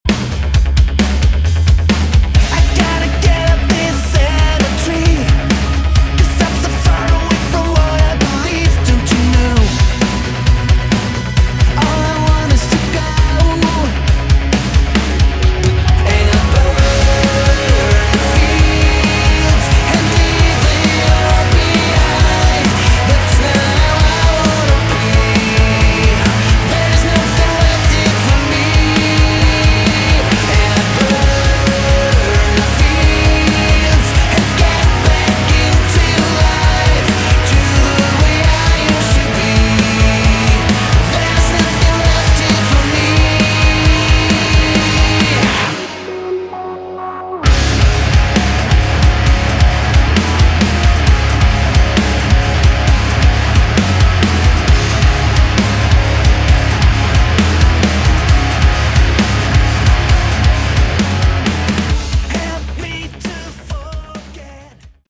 Category: Modern Hard Rock
vocals
bass
keyboards, guitars
drums